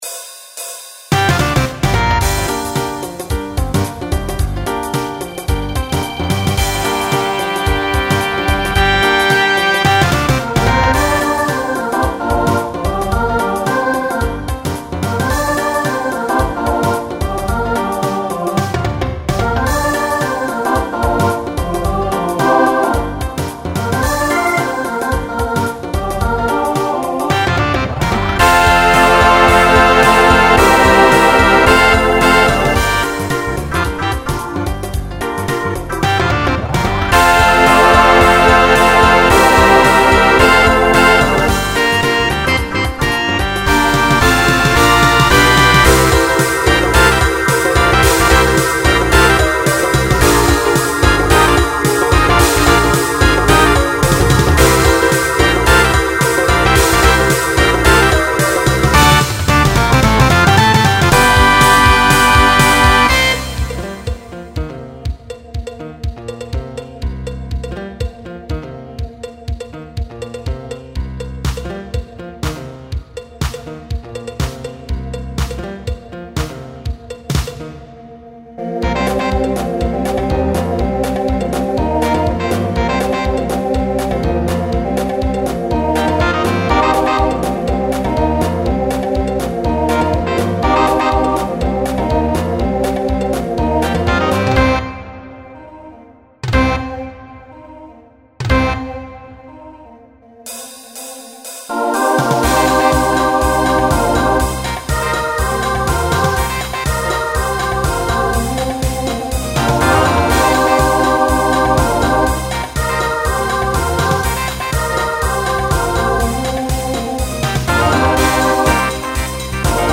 Pop/Dance , Rock
Instrumental combo
Voicing SATB